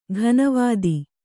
♪ ghana vādi